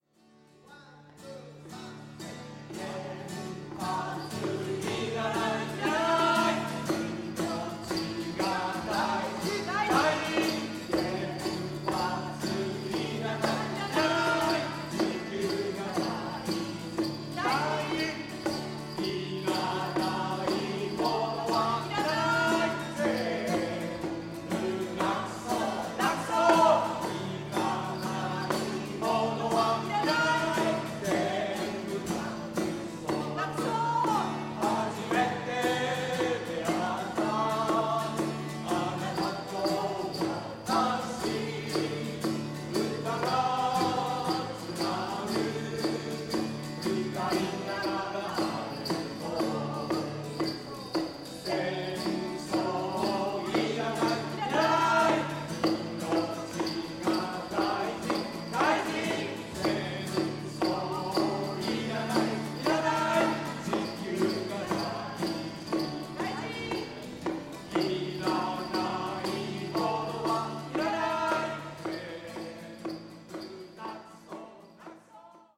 オリジナルコンサート　総評
ギター